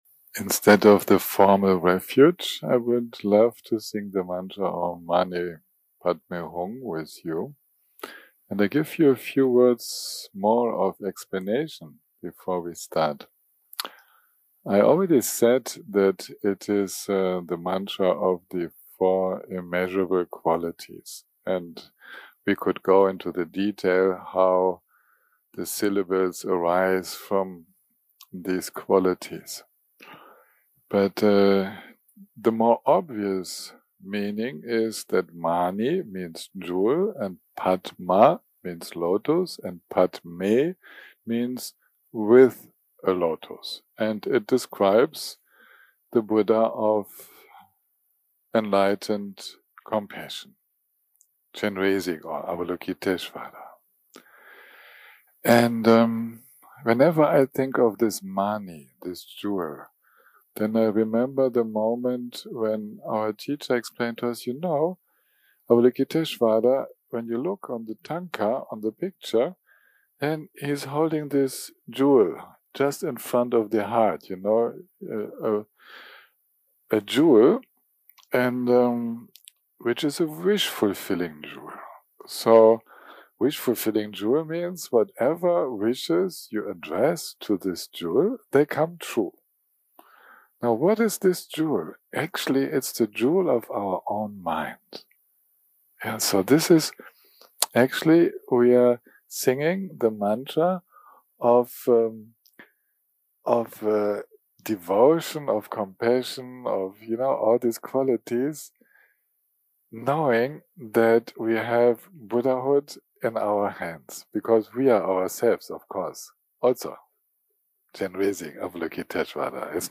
day 6 - recording 20 - Afternoon - Chat + Guided Meditation - Dealing With Stress, Effort, Relaxation, Restlesness & Tiredness.
0:00 0:00 סוג ההקלטה: Dharma type: Dharma Talks שפת ההקלטה: Dharma talk language: English